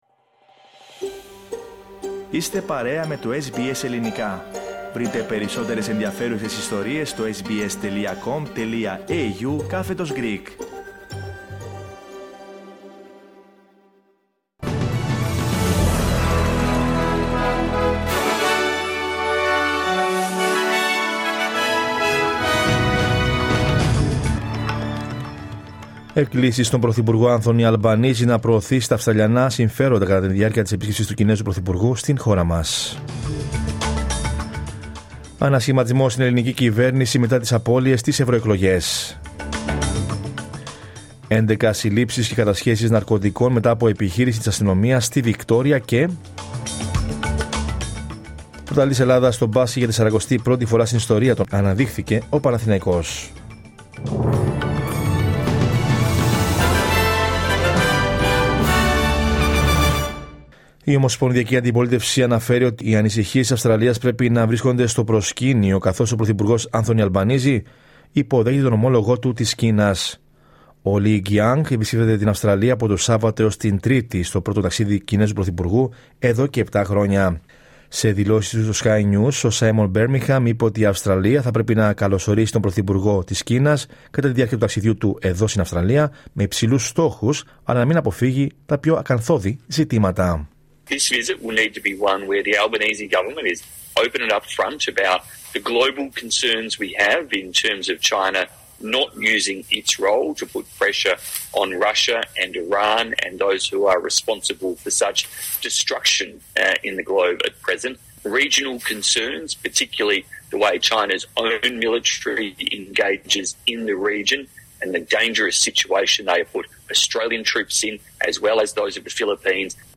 Δελτίο Ειδήσεων Σάββατο 15 Ιουνίου 2024